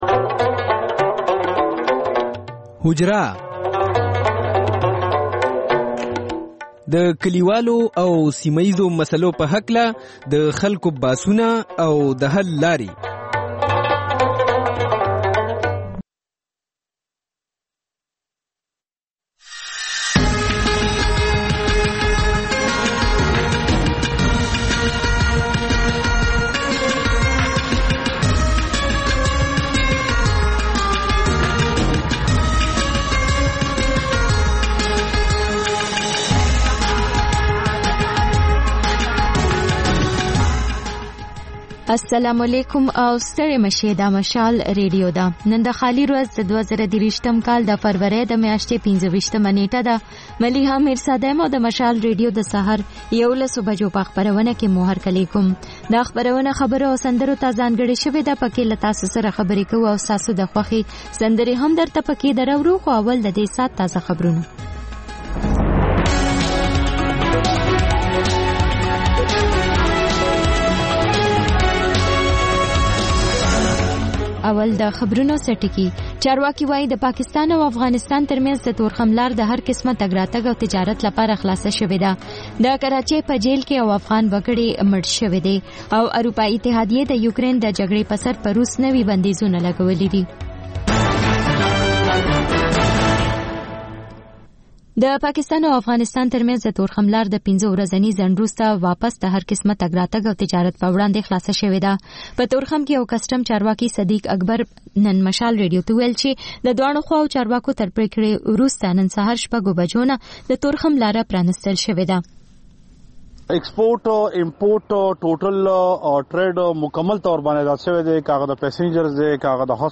په دې خپرونه کې تر خبرونو وروسته له اورېدونکیو سره په ژوندۍ بڼه خبرې کېږي، د هغوی پیغامونه خپرېږي او د هغوی د سندرو فرمایشونه پوره کول کېږي.